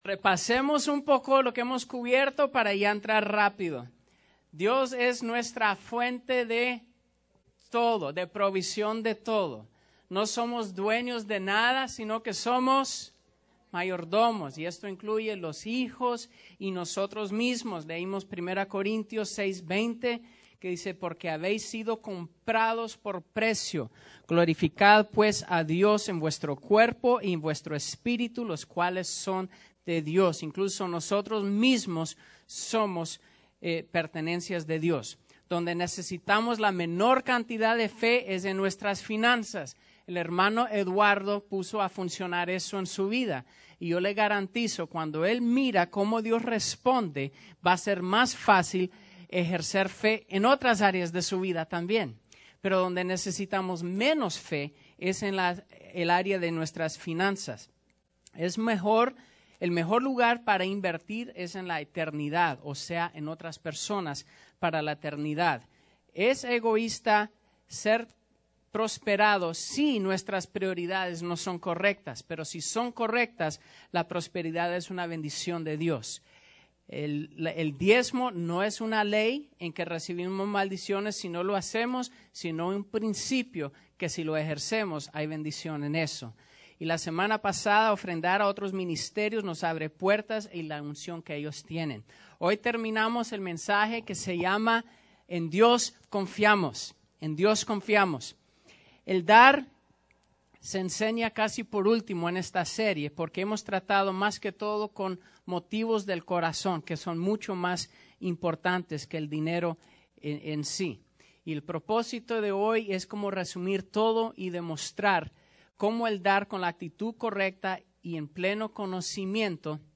Mensaje